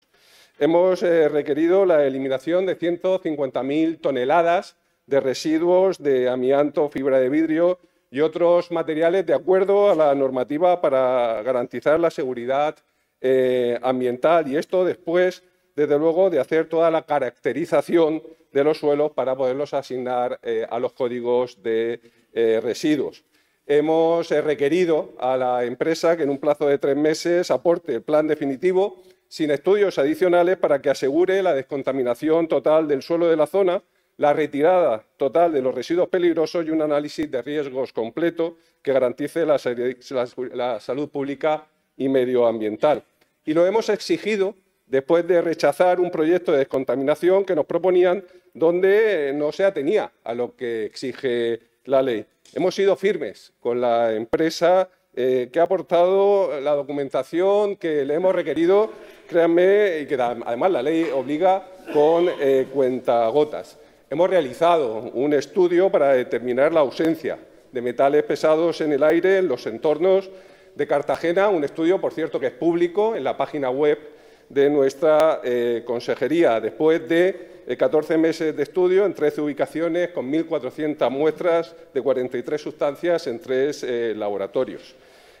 El consejero de Medio Ambiente, Universidades, Investigación y Mar Menor, Juan María Vázquez, en la Asamblea habla sobre las medidas adoptadas por la Consejería en los terrenos de Zinsa.